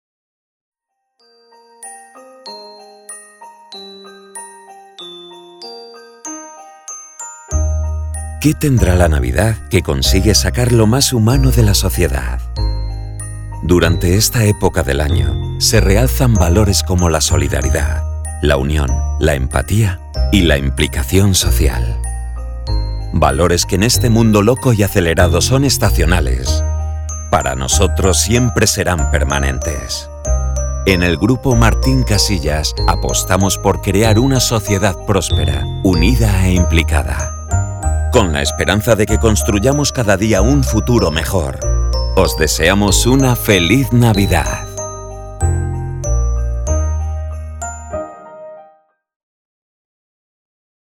Male
Madrid nativo
Microphone: Neumann Tlm 103